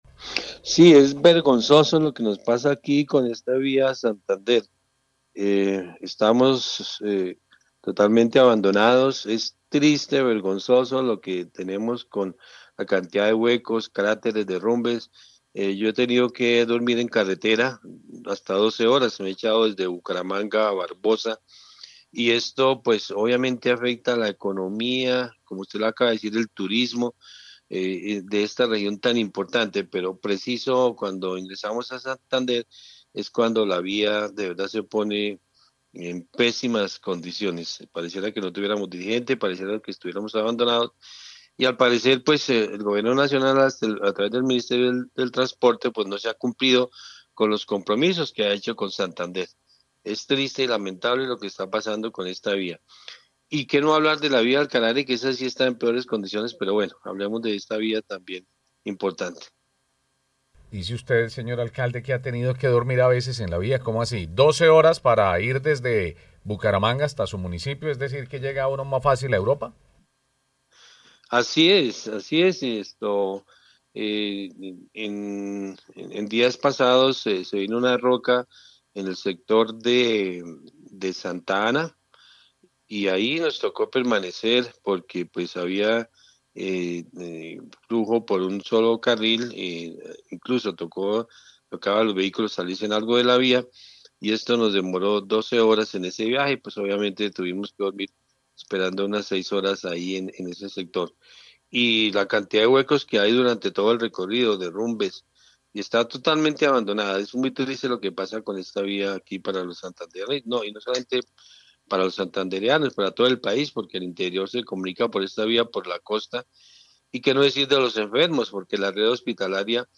Orlando Ariza, alcalde de Vélez, Santander habla del mal estado de la vía entre Bogotá y Bucaramanga